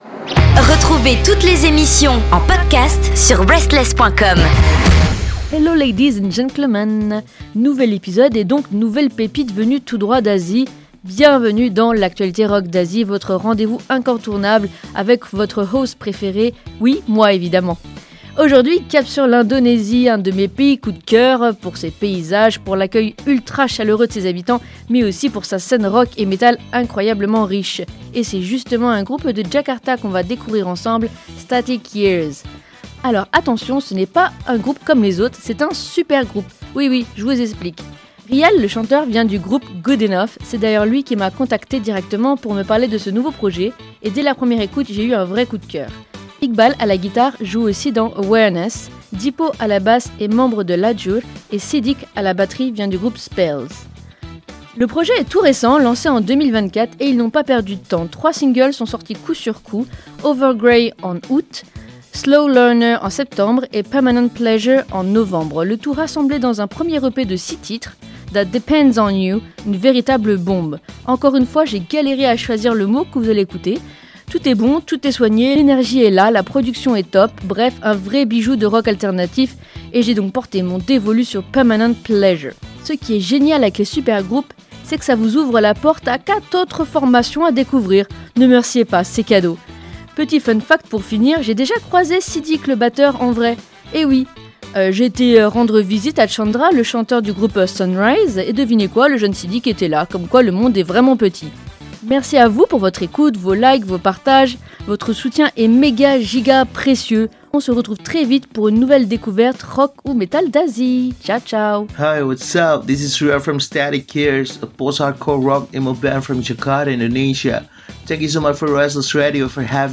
Le groupe STATIC YEARS revient fort avec un EP 6 titres ultra solide, et aujourd’hui on plonge dans l’un de ses morceaux phares : « Permanent Pleasure« . Un titre percutant, entraînant, à l’image du style unique du groupe.